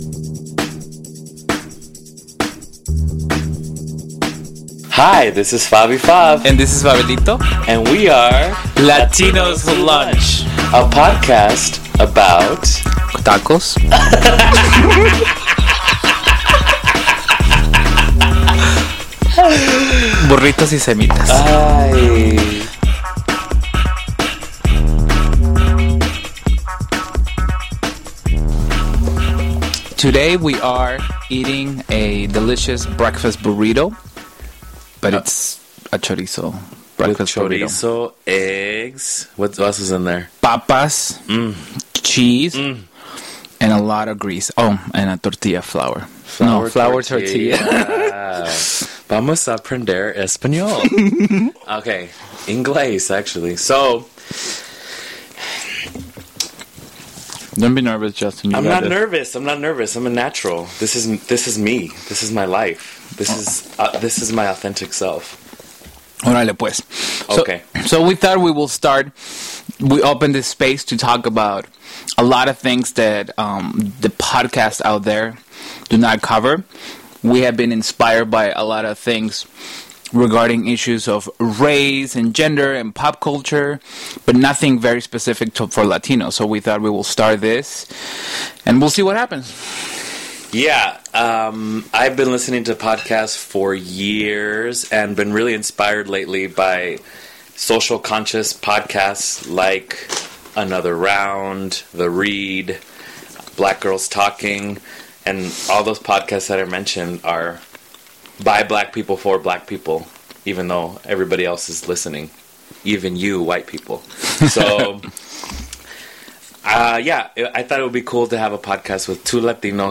Get ready to hear a lot of mouth breathing and chewing noises as they talk about their identity.